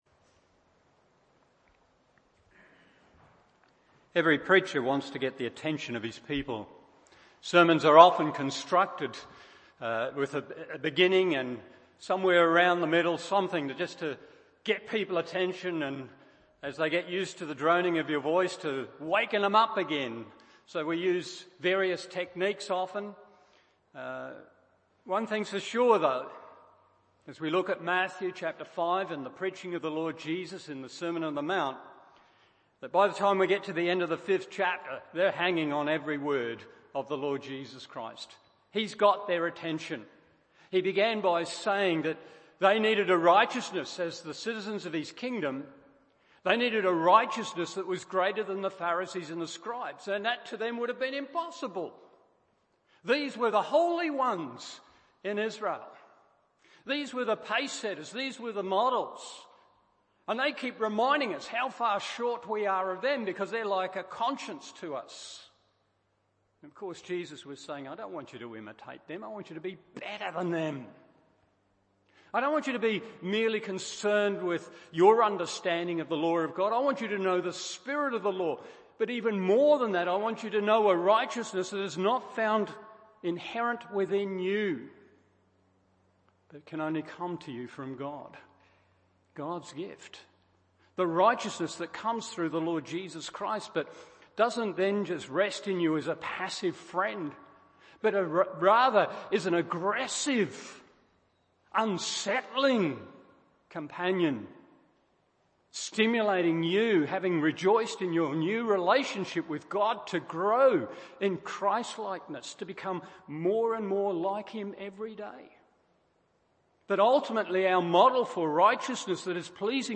Morning Service Matt 5:43-48 1. A pregnant embrace 2. A practical expression 3.